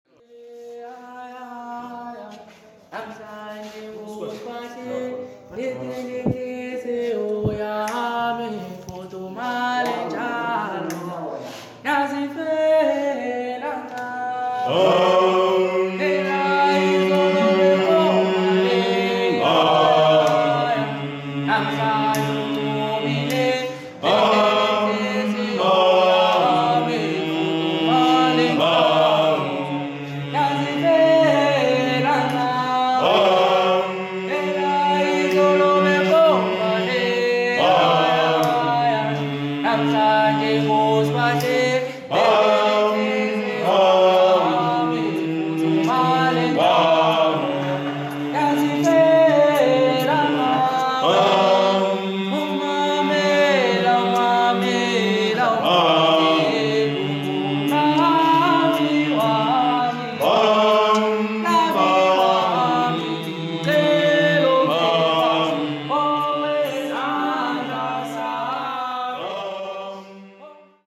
Gwijo